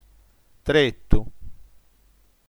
tretu nm tré.tu - ['tɾet:u] ◊